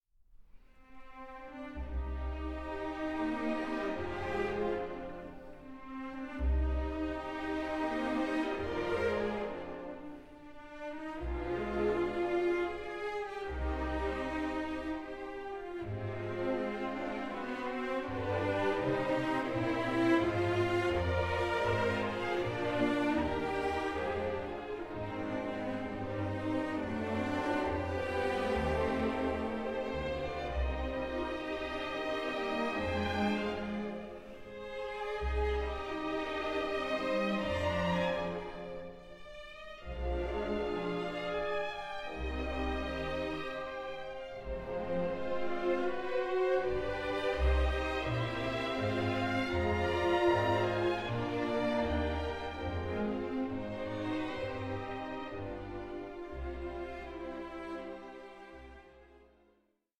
Poco allegretto 6:25